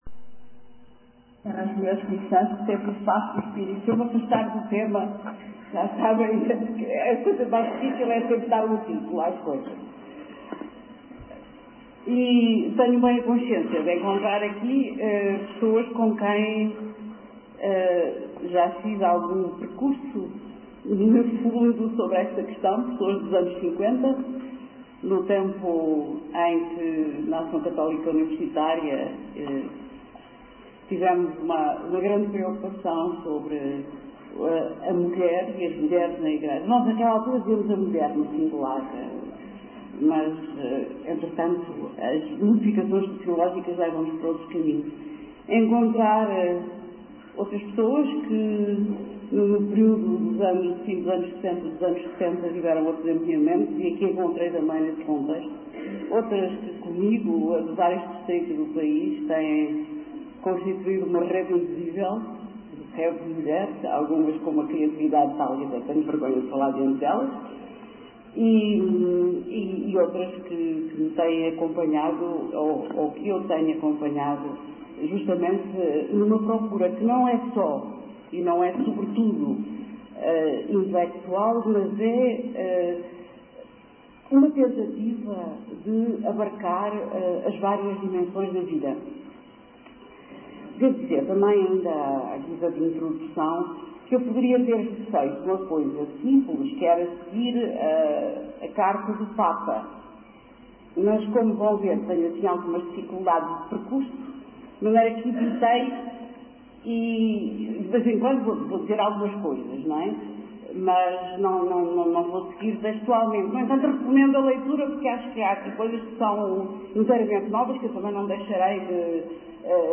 Conf._CRC_5-11-88_Mulheres_na_Igreja.MP3